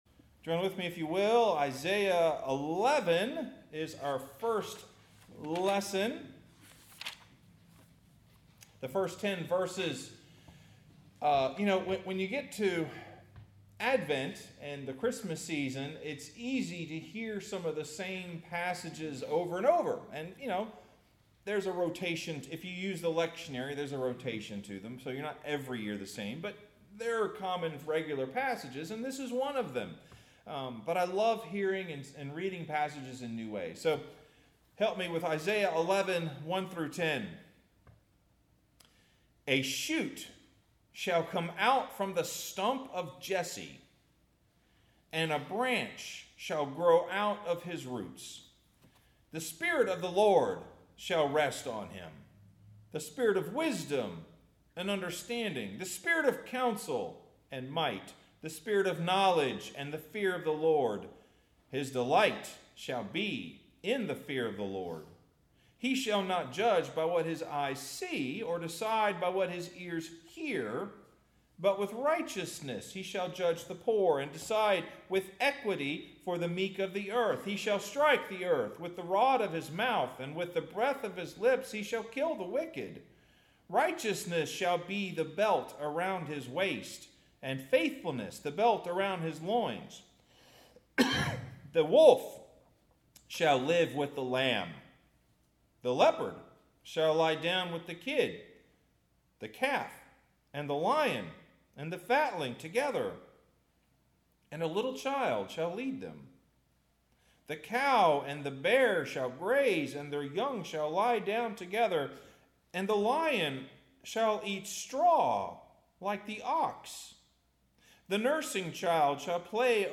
Sermon – What Do You See?